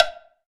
9LWWOODBL.wav